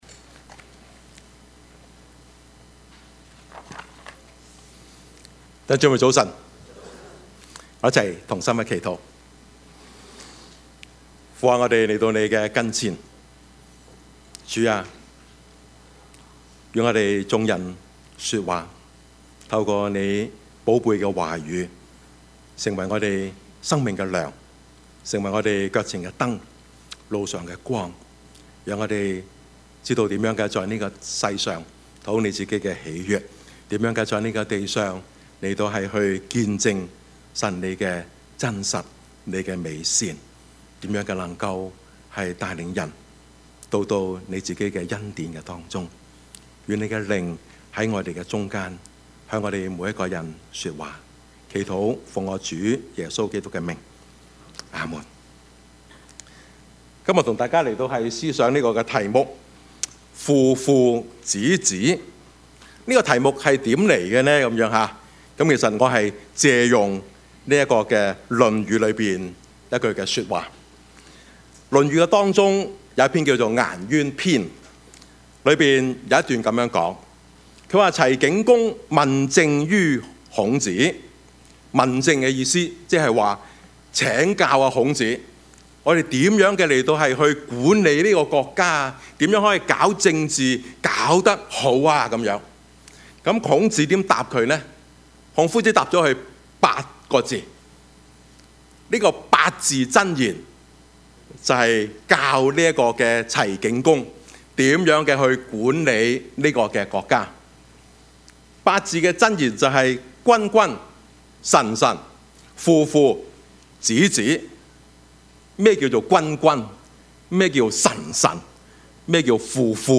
Service Type: 主日崇拜
Topics: 主日證道 « 鲁迅 歷史的一刻 »